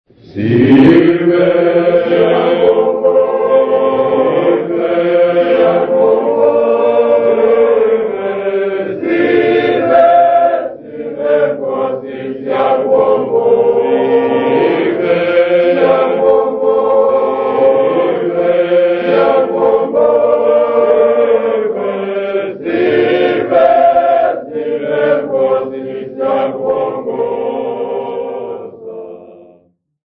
Zingisa Seminary Congregation
Folk music
Sacred music
Field recordings
Unaccompanied Catholic hymn.